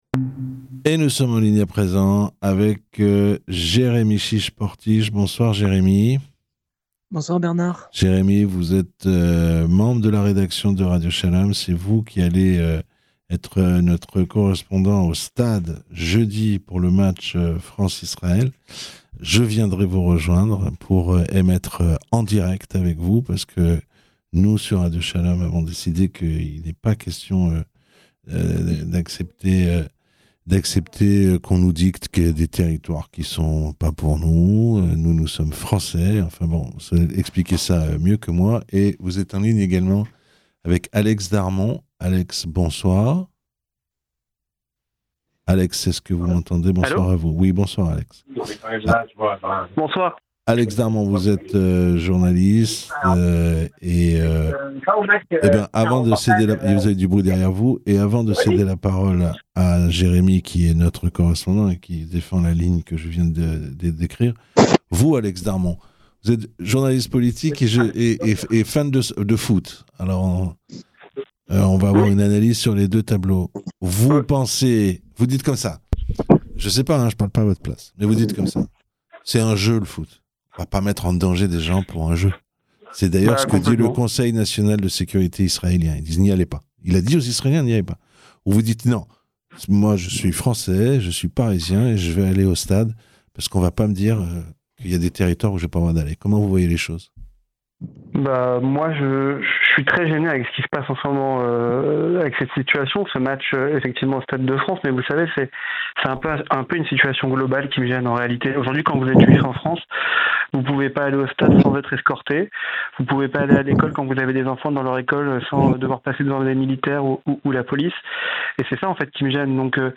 Débat entre 2 journalistes